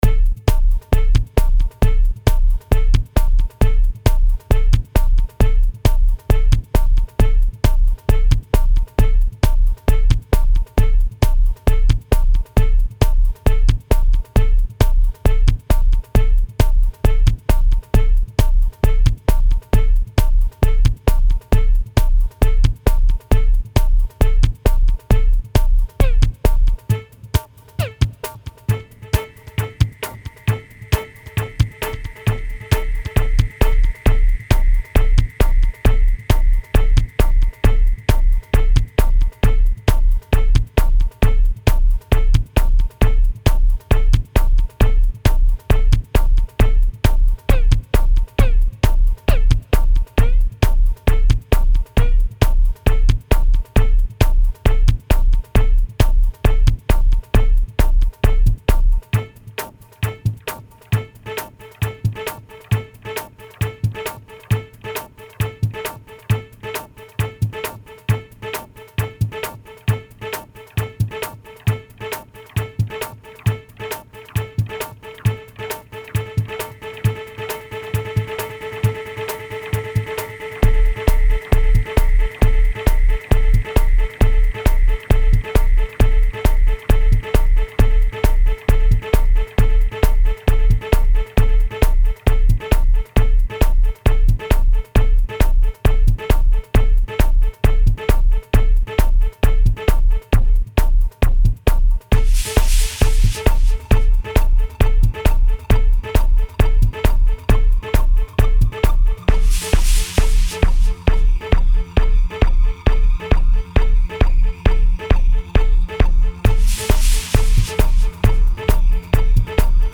Musikstil: Techno